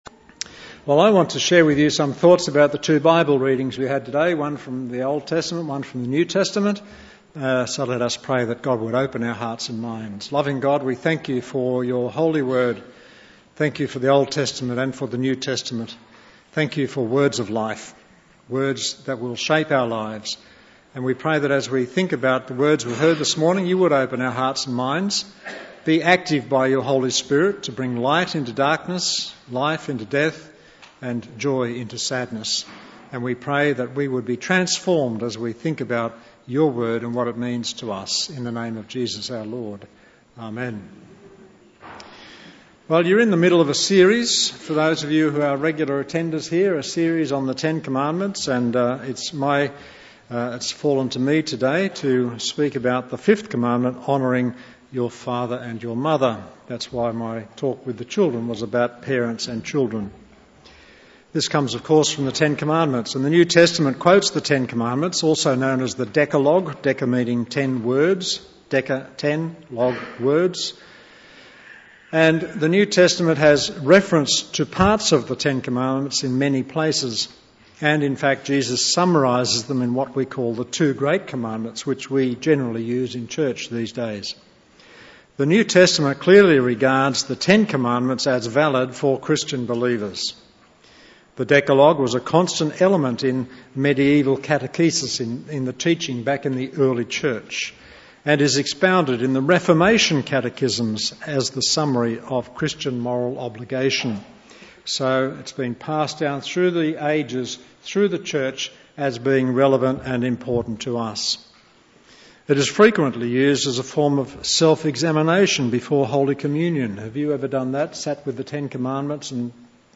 Bible Text: Mark 7:9-23 | Preacher